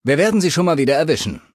Datei:Maleadult01default dialogueandale goodbye 0002e361.ogg
Fallout 3: Audiodialoge